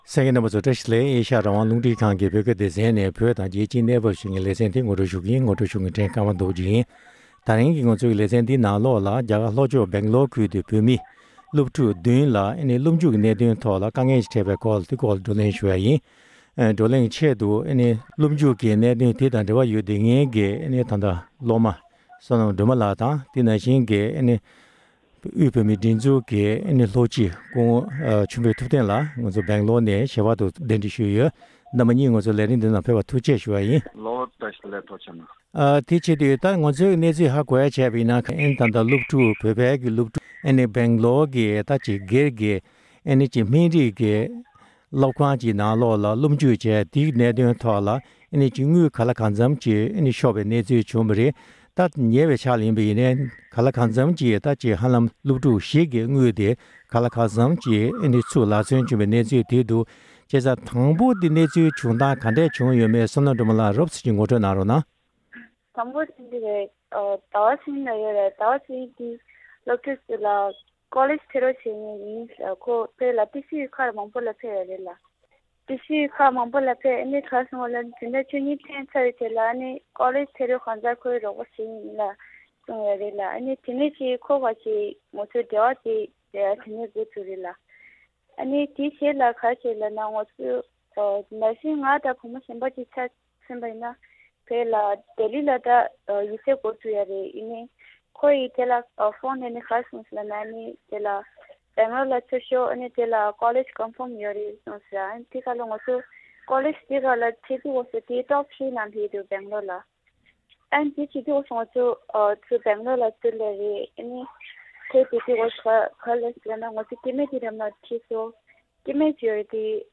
བགྲོ་གླེང་ཞུས་པར་གསན་རོགས་ཞུ༎